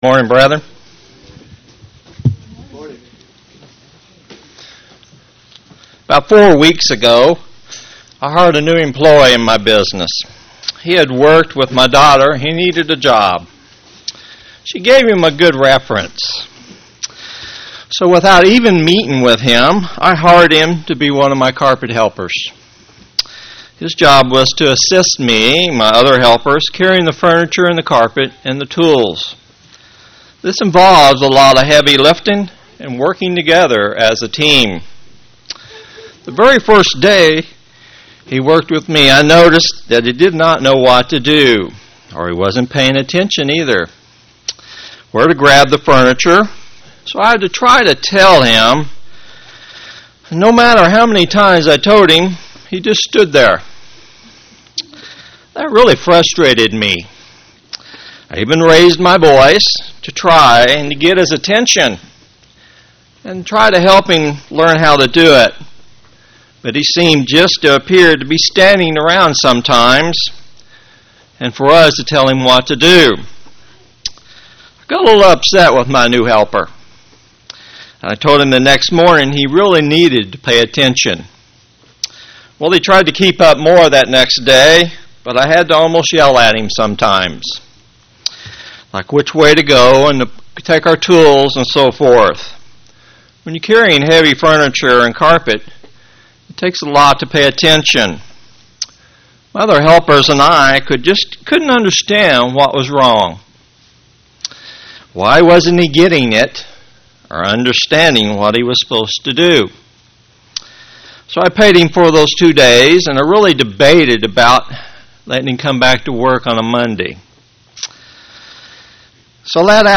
Given in St. Petersburg, FL
UCG Sermon Studying the bible?